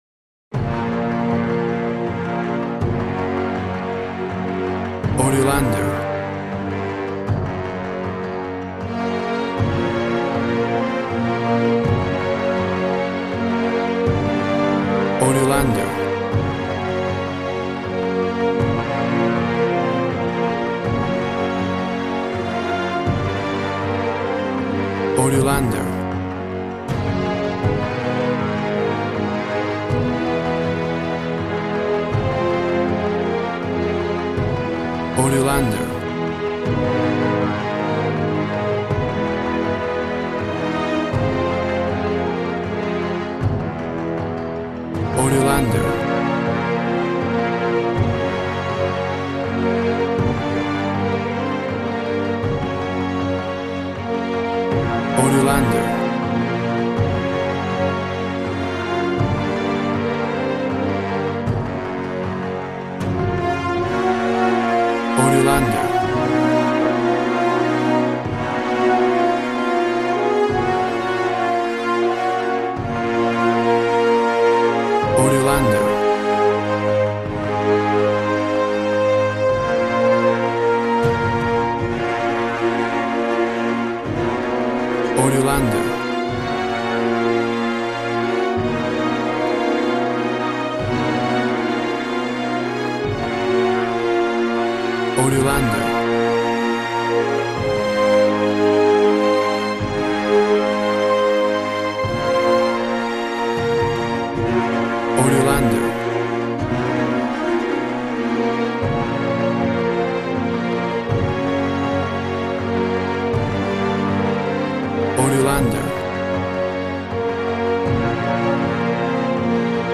WAV Sample Rate 16-Bit Stereo, 44.1 kHz
Tempo (BPM) 80